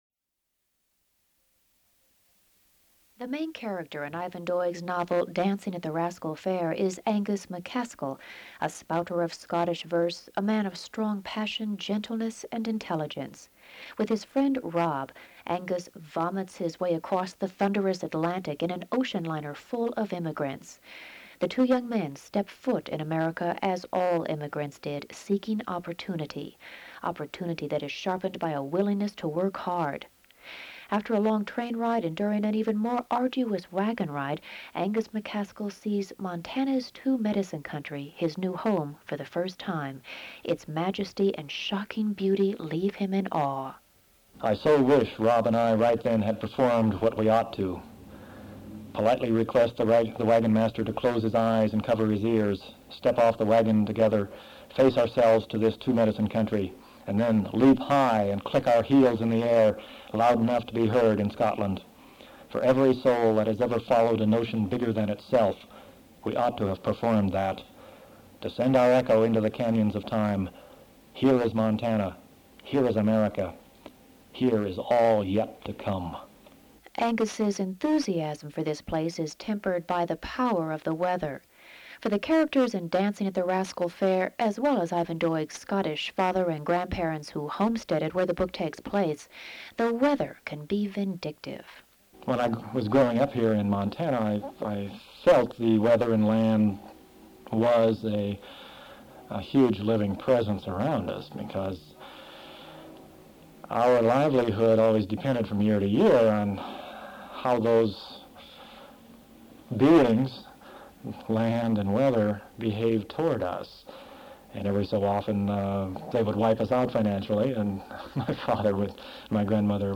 Interviewee  Doig, Ivan
Genre  interviews
Interview